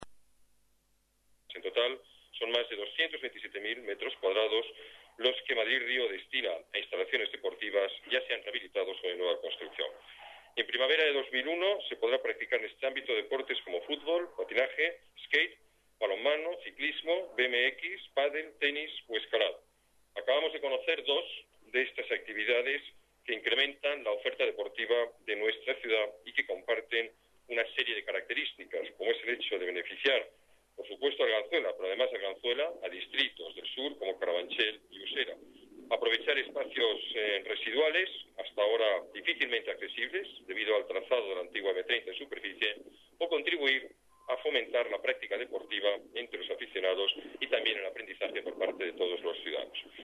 Nueva ventana:Declaraciones alcalde, Alberto Ruiz-Gallardón: Madrid Río, actividades deportivas